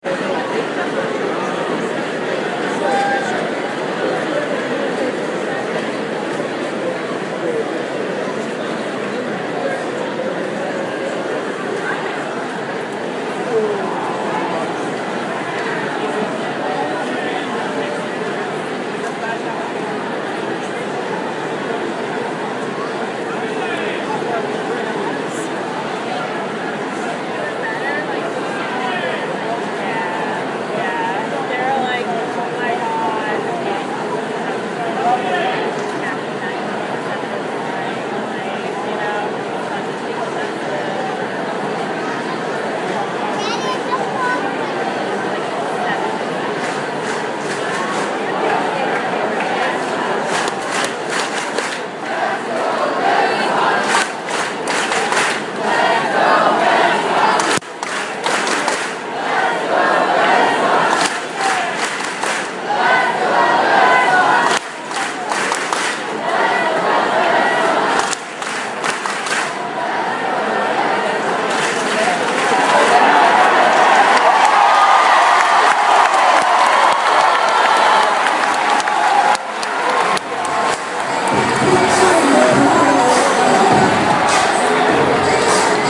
描述：芬威球场，波士顿2015年8月22日
标签： 红袜 观众欢呼 球场 波士顿 掌声 喋喋不休 芬威 棒球 现场录音 沃拉 氛围 体育场 体育 欢呼 公园 外观 鼓掌 人群
声道立体声